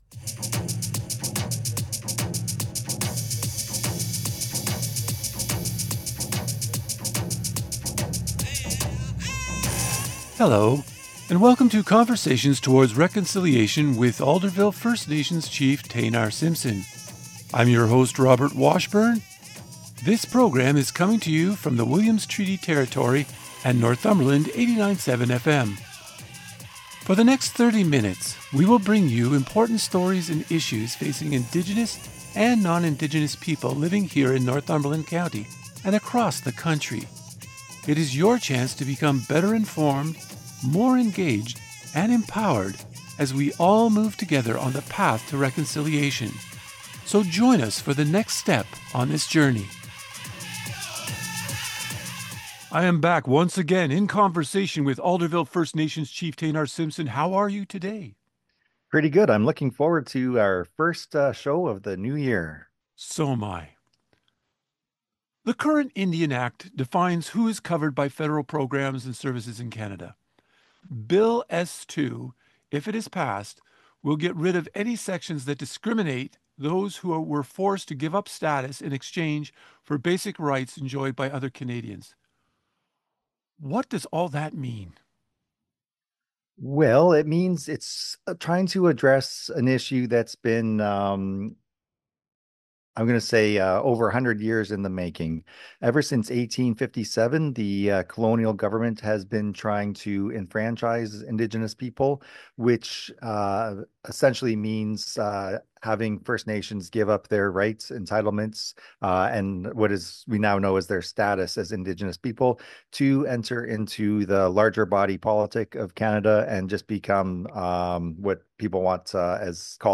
Alderville Chief Taynar Simpson talks about Bill S-2. It is legislation the Senate approved to address historical issues of enfranchisement.